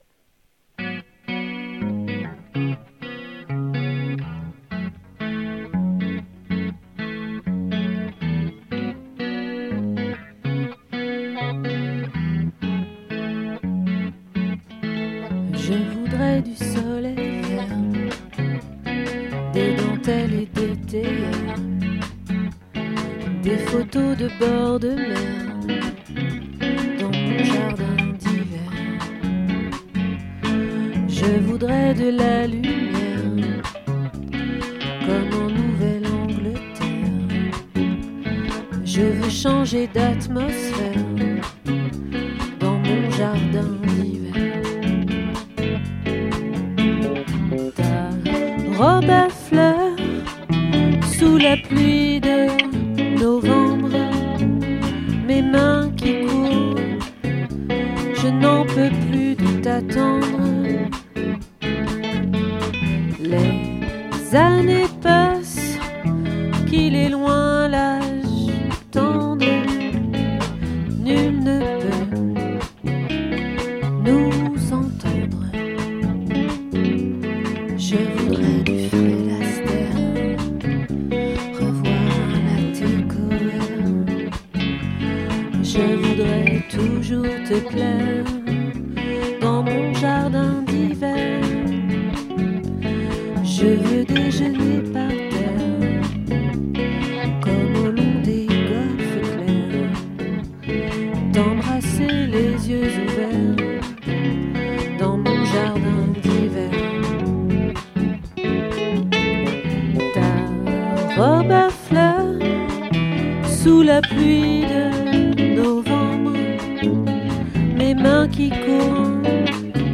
🏠 Accueil Repetitions Records_2022_02_02